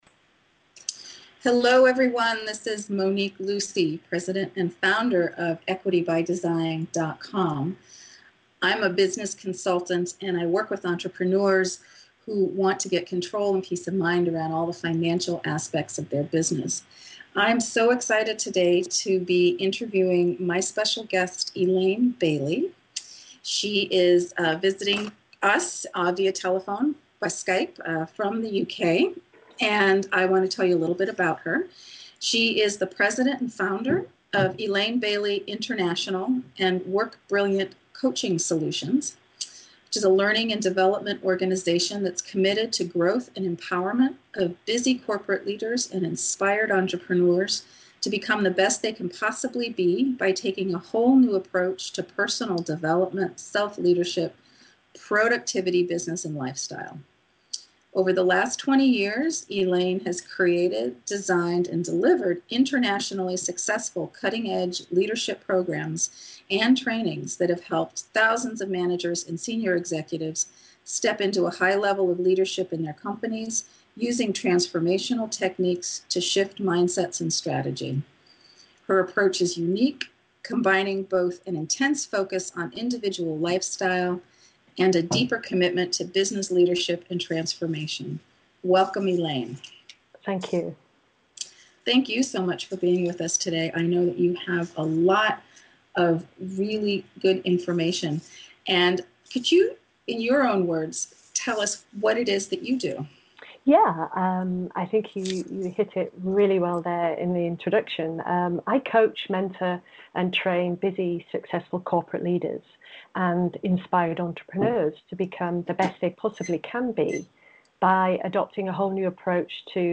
When you listen, you’ll hear really loud rustling in two or three places in the beginning. That’s me futzing around with my paper notes too close to the microphone.